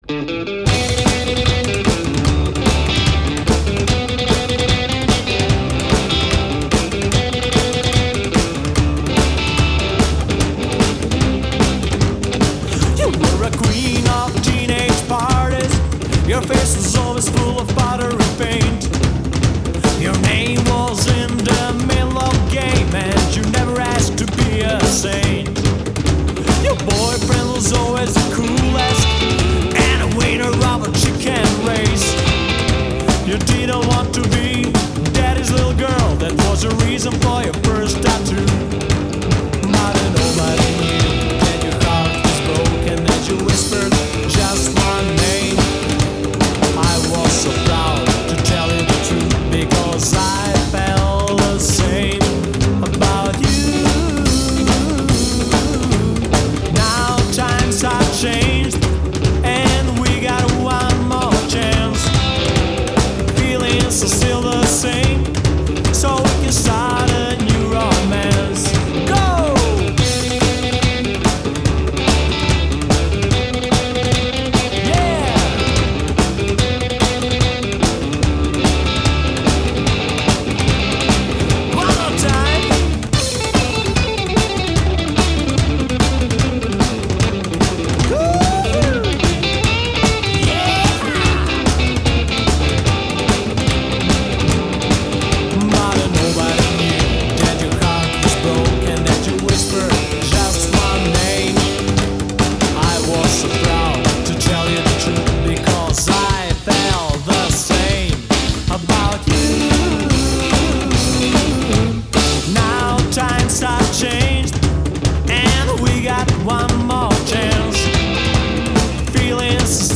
Rockabilly trio